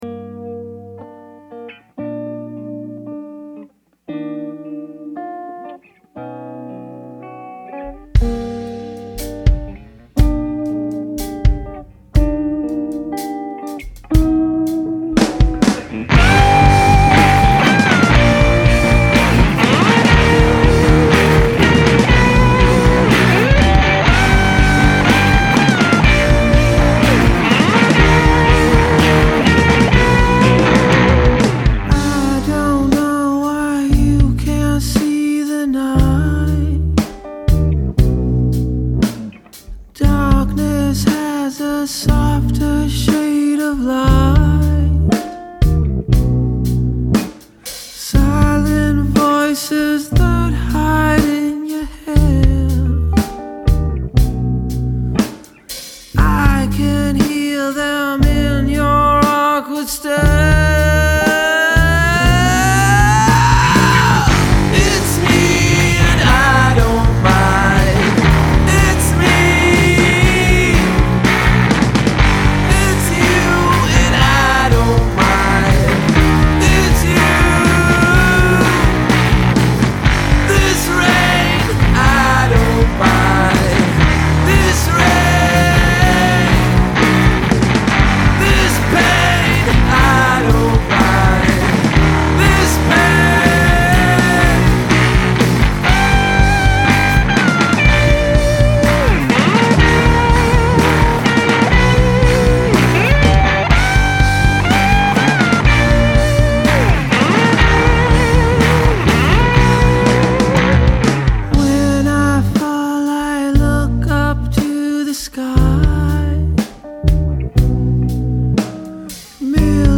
Home Mix feedback please
Hello there, this is a track my friend and I recorded. It's not quite finished yet, we are definitely re-recording the guitar solo since it's a bit sloppy. My problem is getting the chorus to hit a little harder than the verse. Seems to be pretty flat right now...